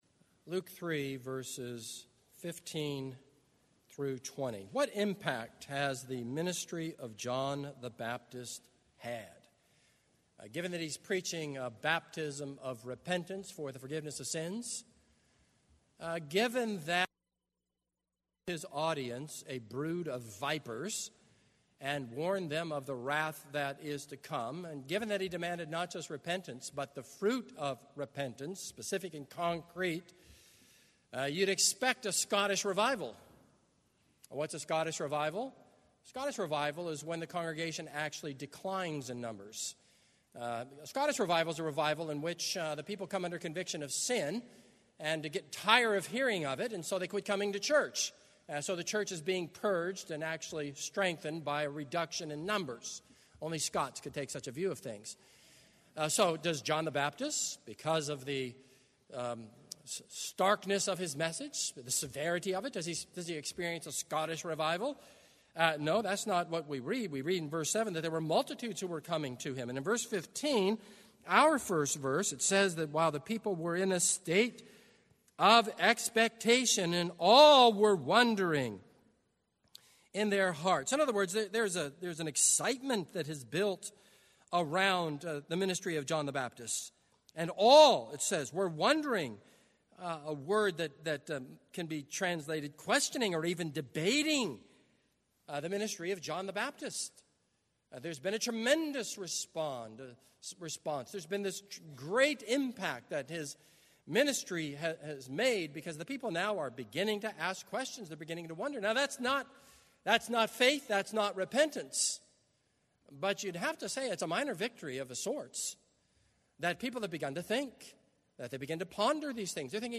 This is a sermon on Luke 3:15-20.